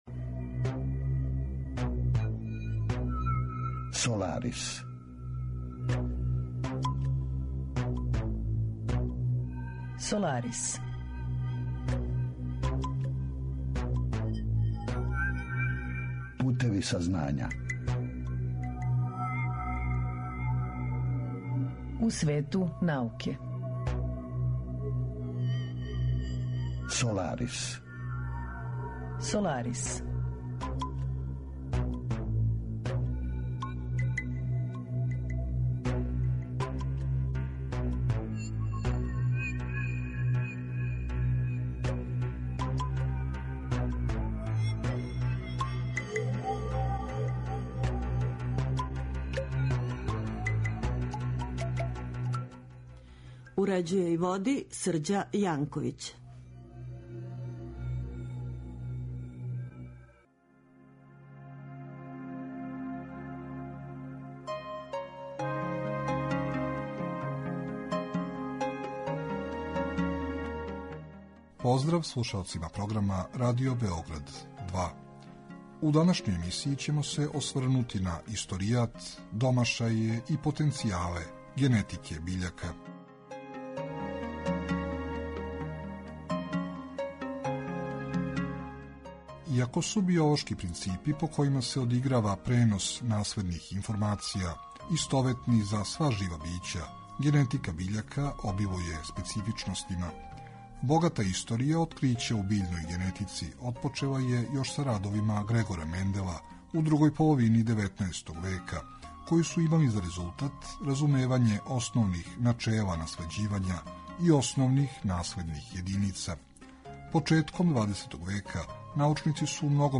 Разговор је први пут емитован 11. фебруара 2018.